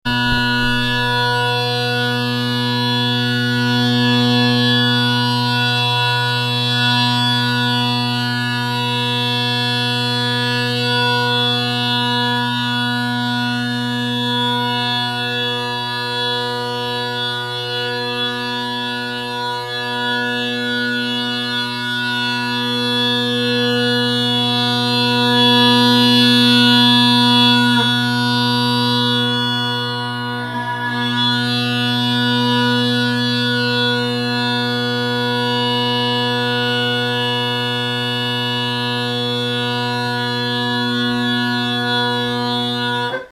Center + Colin Kyo drone reeds
Drone Sounds of the GHB
3/4 John Center ca. 1900 bagpipe made of Cocus wood.
Note that at one point I shut the middle tenor off with my finger and then promptly reduced the pressure to let it come back in. I walk around.
As a personal comment, these drones are very bright but not brassy.